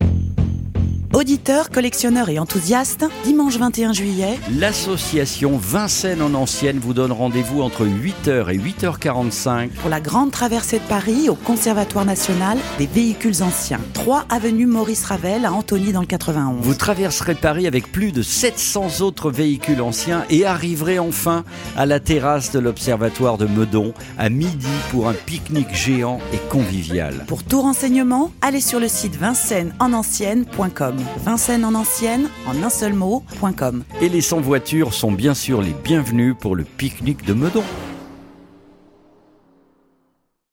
BANDE ANNONCE :
PUBLICITE_VINCENNE_EN_ANCIENNE.mp3